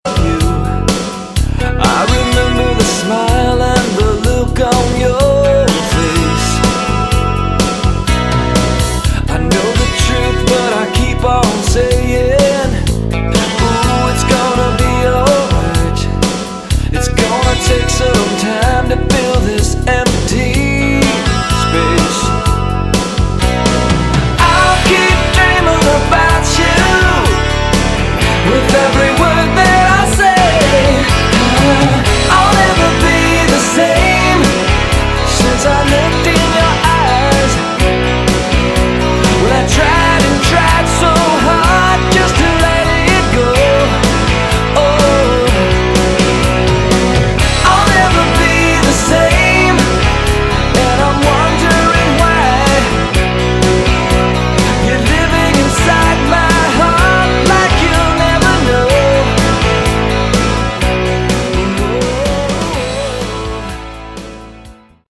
Alt Mix